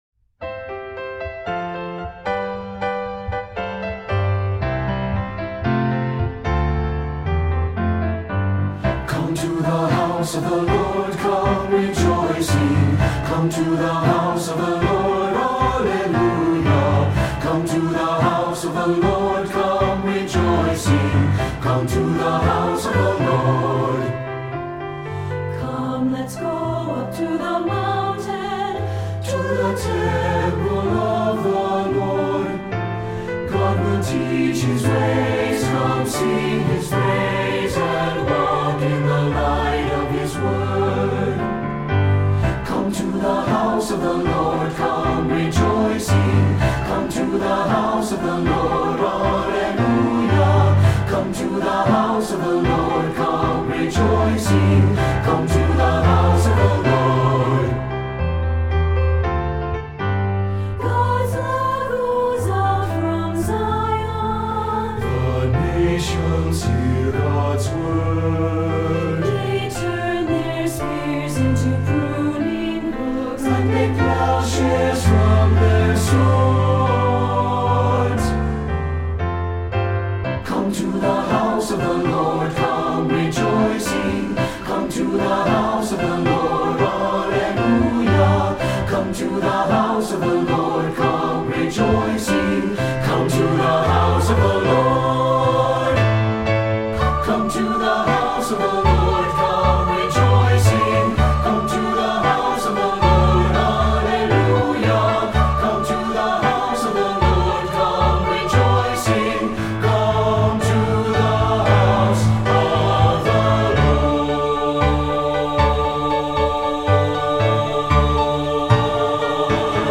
Voicing: SAB and Piano